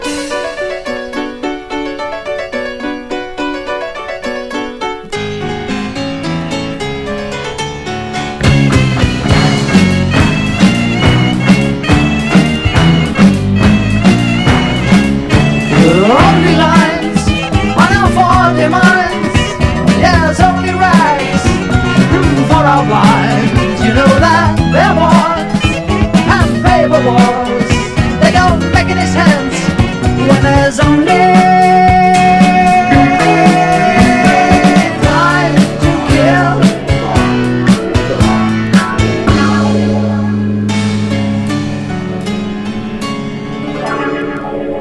JAZZ / DANCEFLOOR / DRUM BREAK / RARE GROOVE / FUNK / LOUNGE
ラウンジ系のクラブ・ジャズ & レア・グルーヴ・クラシック盛りだくさんのグレイト・コンピ！